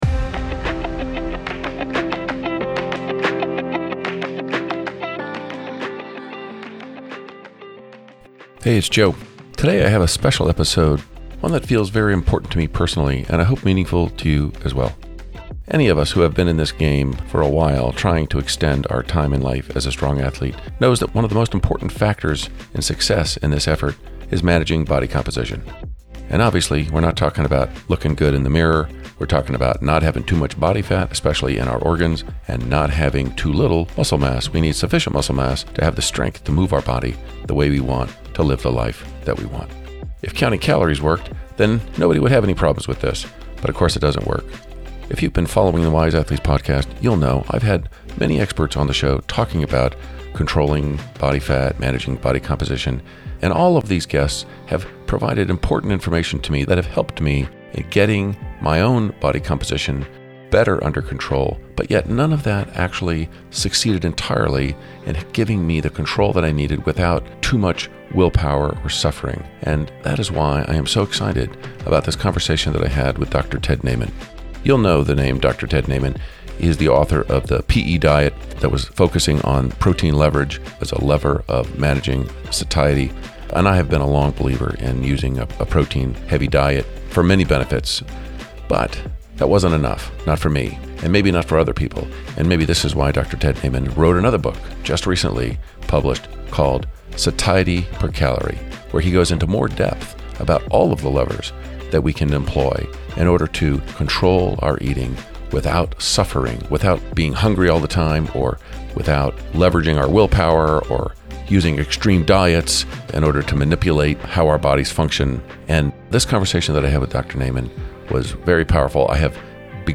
1 Permitting reform and the politics of building the grid | Live from the ACORE Grid Forum in Washington, D.C. 47:28